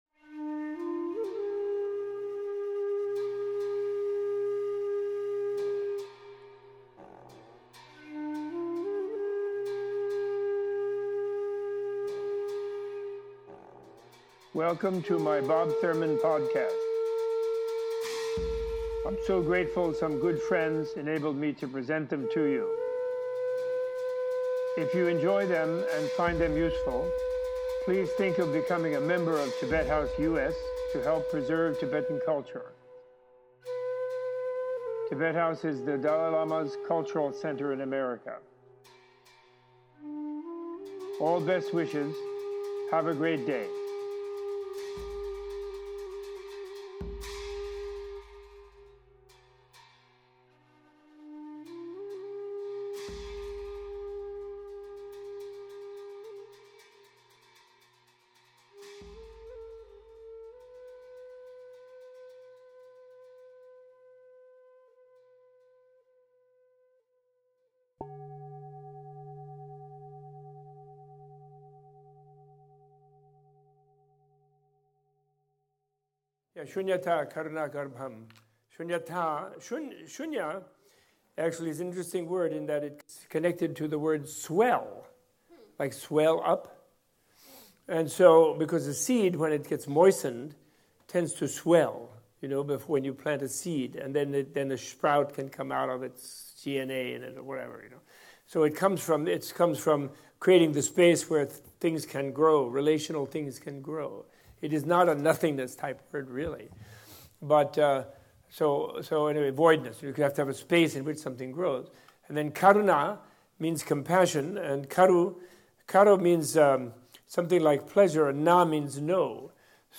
In this questions and answers session Professor Thurman and Sharon Salzberg discuss the interconnected nature of emptiness, compassion, and loving-kindness and the use of mandalas in meditation.